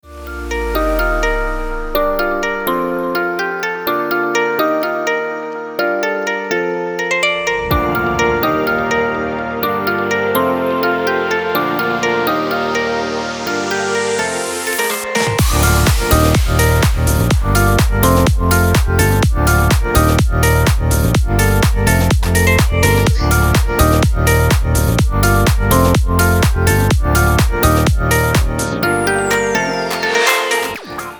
• Категория: Красивые мелодии и рингтоны